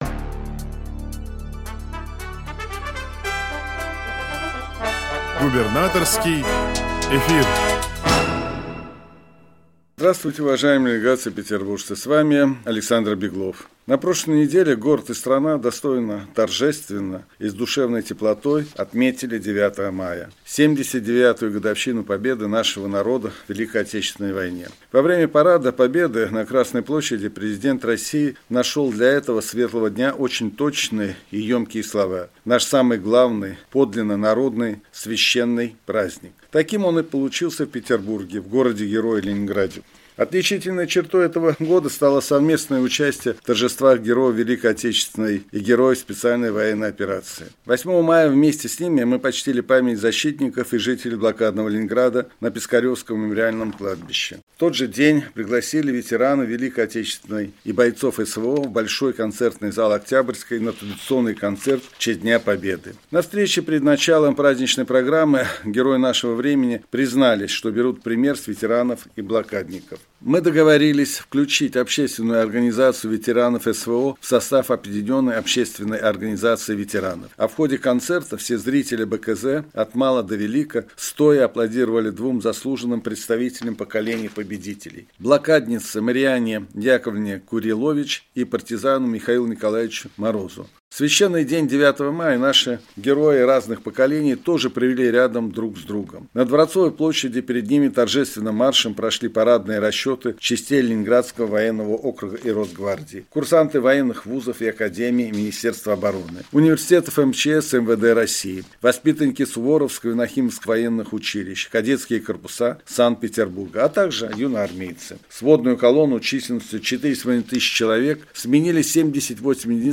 Радиообращение – 13 мая 2024 года